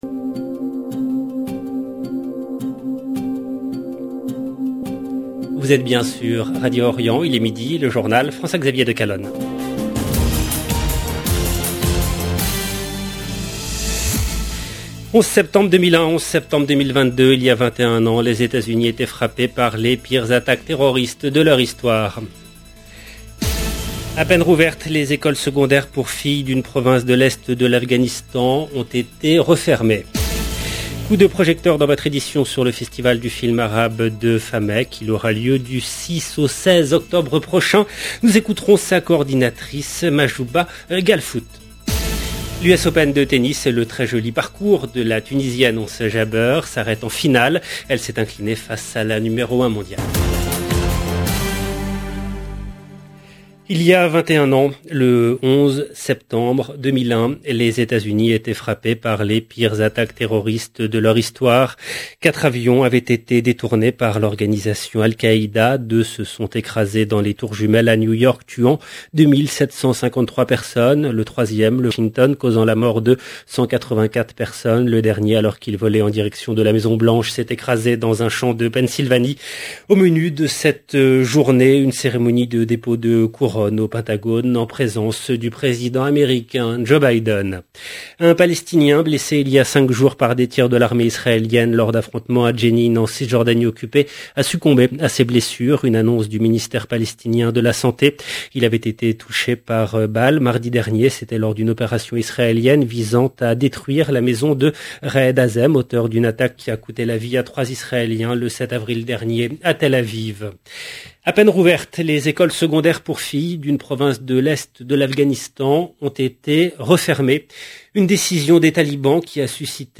LE JOURNAL DE 12 H EN LANGUE FRANCAISE DU 11/9/2022